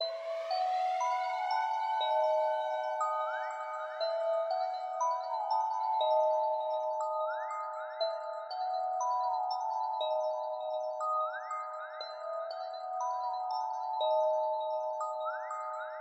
标签： 120 bpm Trap Loops Bells Loops 2.69 MB wav Key : Unknown
声道立体声